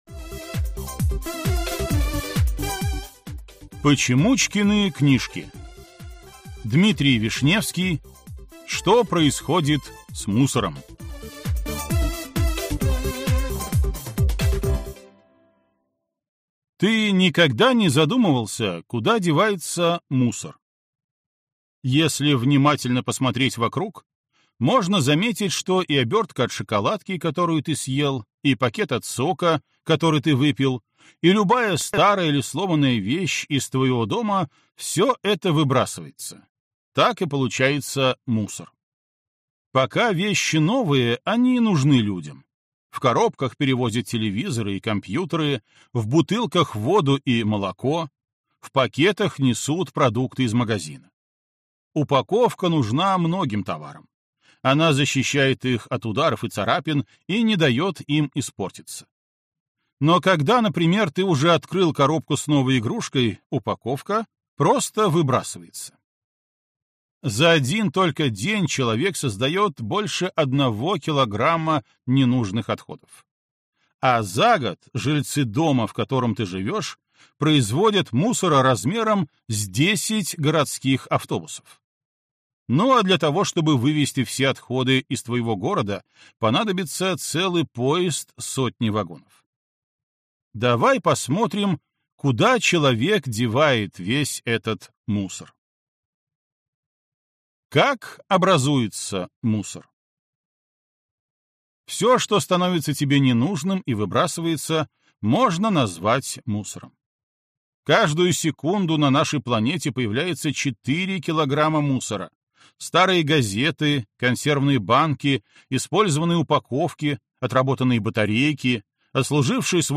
Аудиокнига Что происходит с мусором?